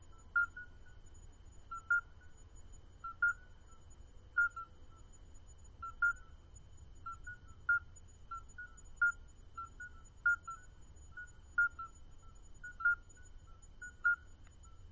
Pas de chants mais comme un "bip" à  intervale très très régulier.
Ce sont bien des alytes accoucheurs alors!
Alytes_obstetricans-choeur.mp3